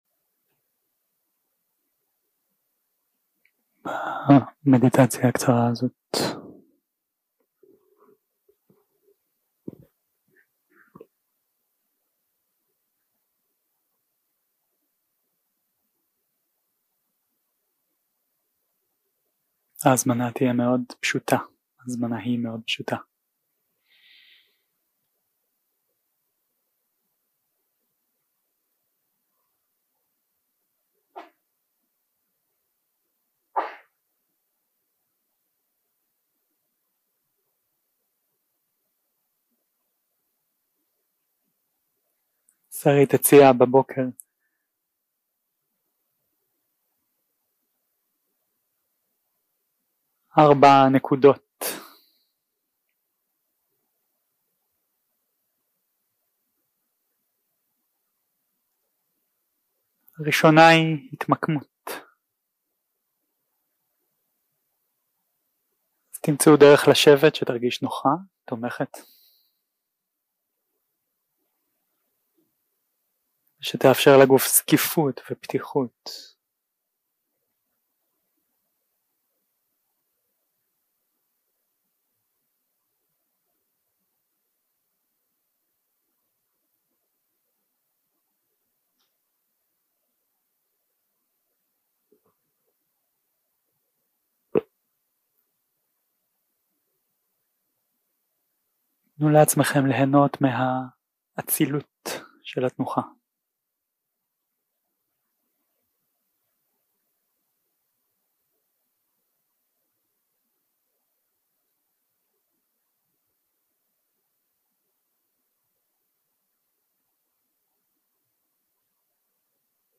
יום 2 - הקלטה 4 - ערב - מדיטציה מונחית
Dharma type: Guided meditation